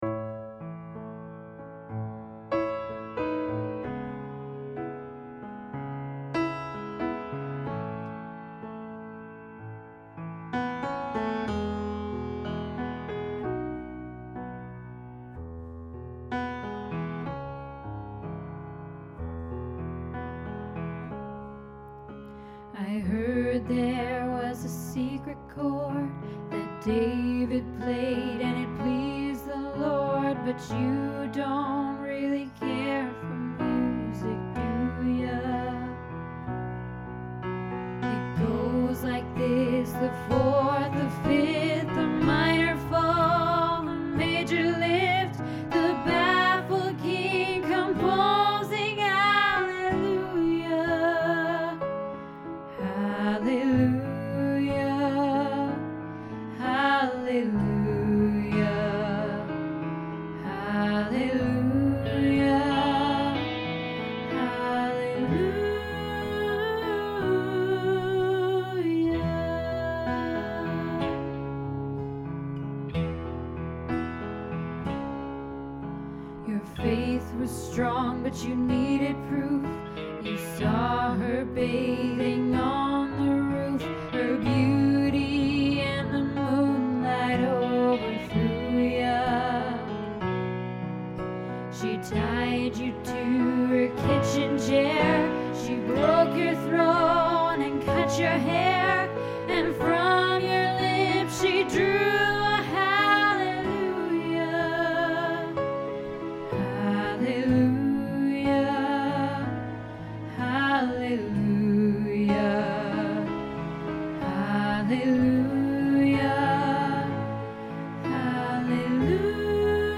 Hallelujah Alto Lead